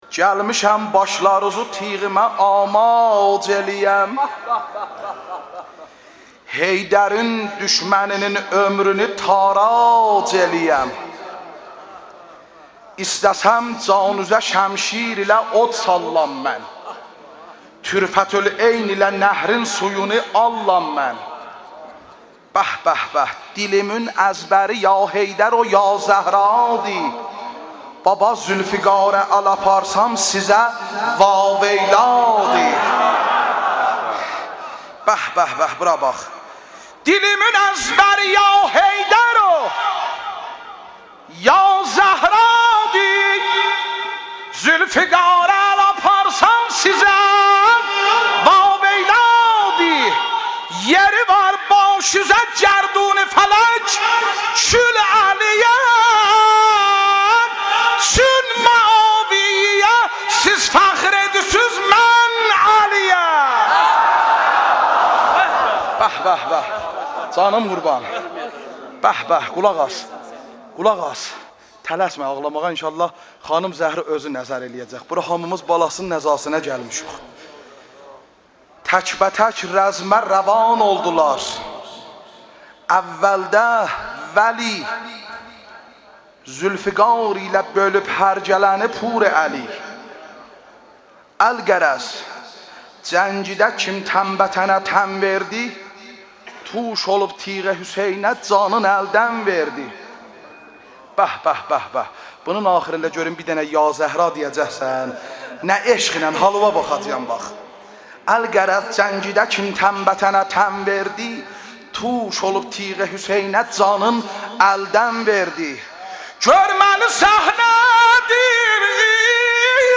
این مراسم در مسجد جامع اردبیل برگزار شده است.
۳. گلمیشم باشلاروزون تیغیمه آماج ائلیم (شعر خوانی)